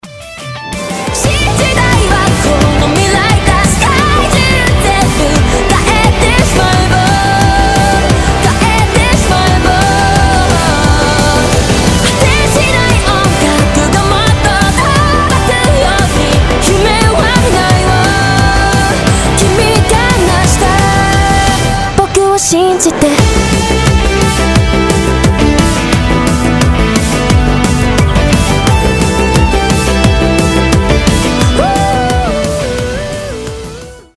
• Качество: 320, Stereo
громкие
быстрые
synthwave